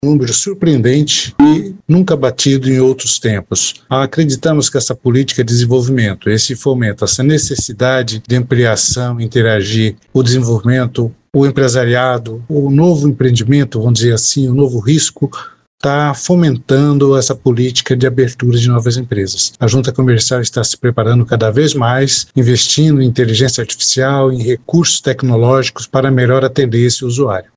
Em entrevista à FM Educativa MS 104.7 o diretor-presidente da Jucems, Nivaldo Domingos da Rocha, falou sobre os dados, “o estado vem mantendo um ritmo elevado de abertura de empresas. Ele destaca que Mato Grosso do Sul tem apresentado resultados expressivos e sucessivos nesse indicador”.